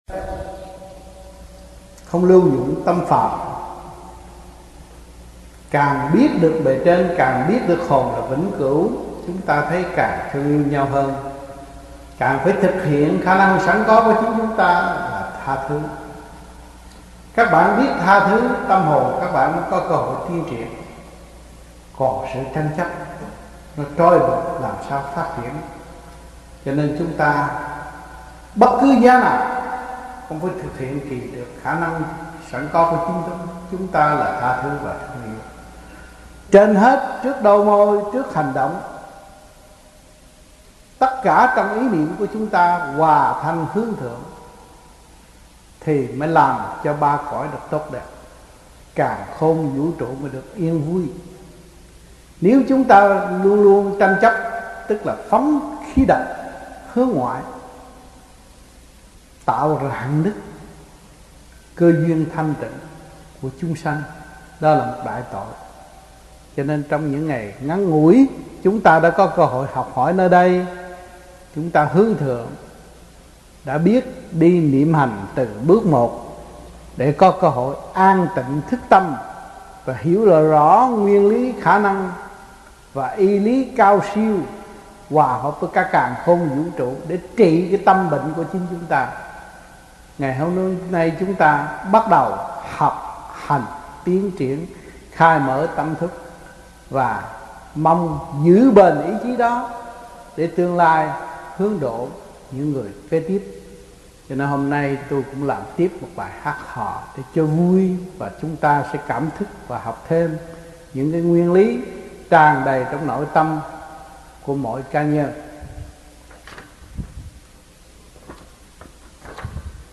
1986-06-07 - TV HAI KHÔNG - KHÓA BI TRÍ DŨNG 5 - BÀI GIẢNG